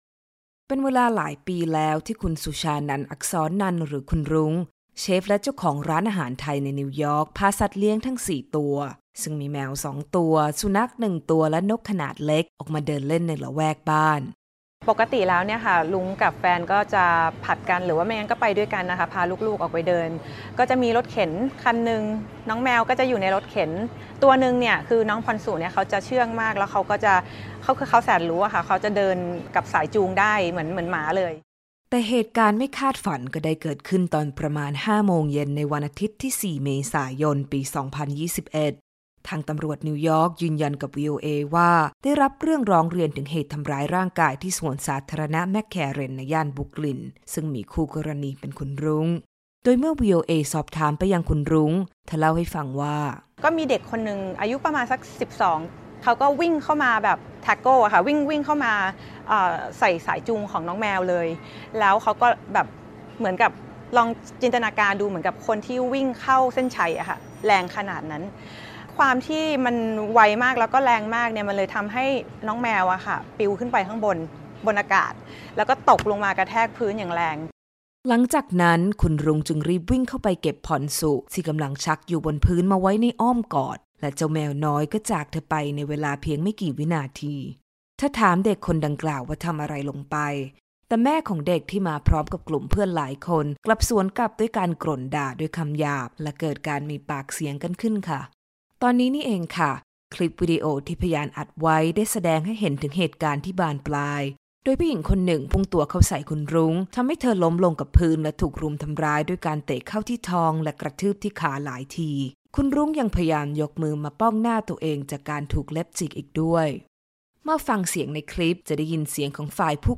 สัมภาษณ์: สาวไทยในนิวยอร์กร้องขอความยุติธรรมหลังตนเองเเละสัตว์เลี้ยงถูกทำร้าย